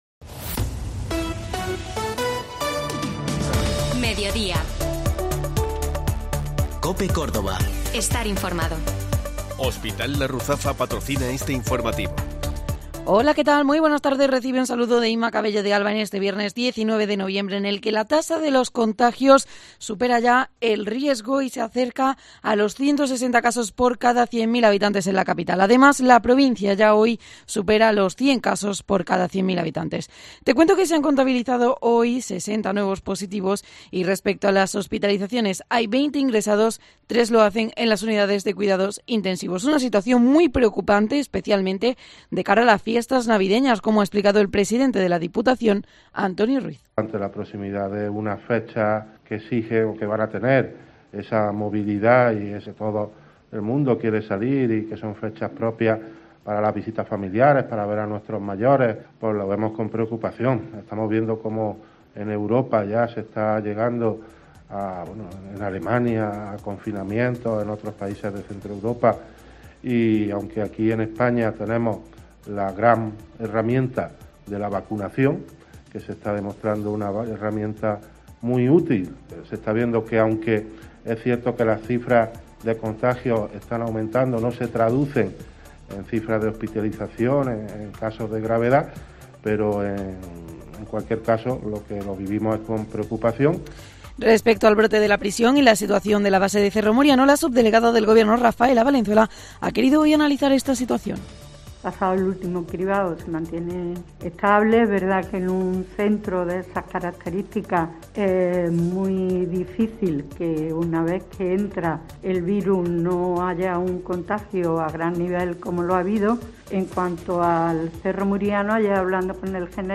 Informativo Mediodía COPE Córdoba